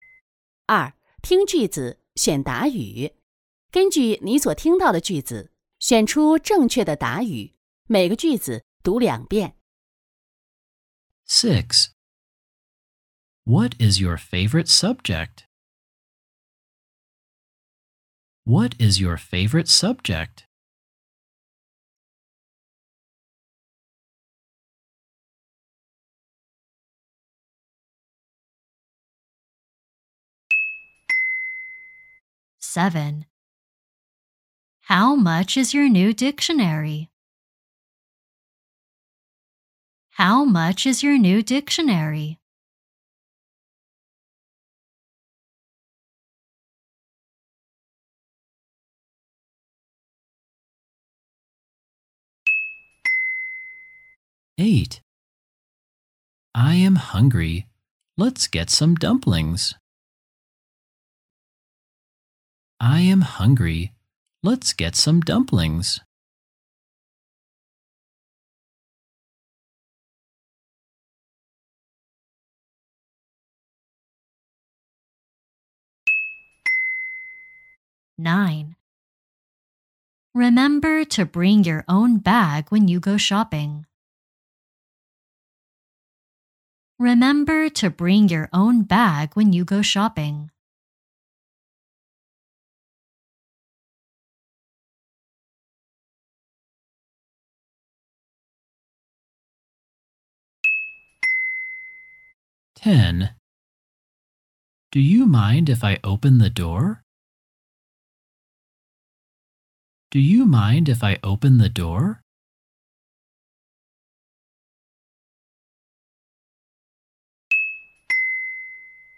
根据你所听到的句子，选出正确的答语。每个句子读两遍。